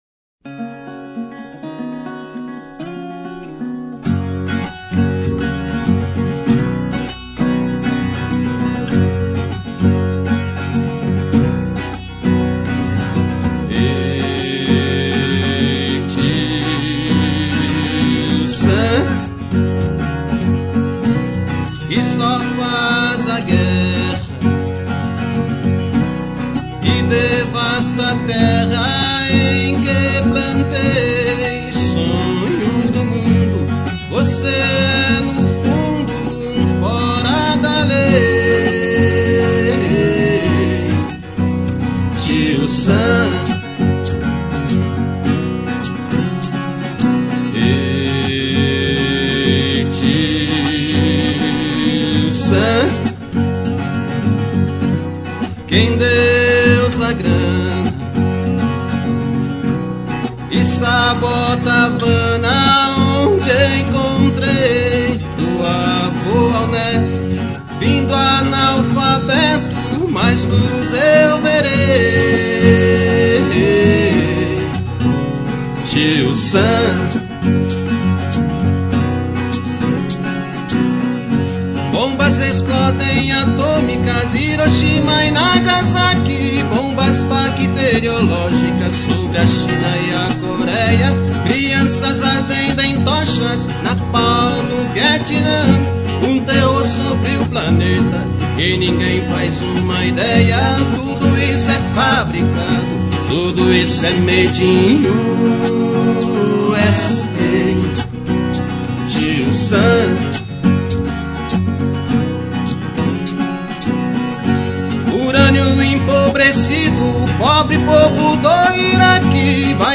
first voice
viola caipira (an instrument used by brazilian backwoodsmen players)
second and third voices
viola-bass caipira
percussao
shake, xequere, cimbalo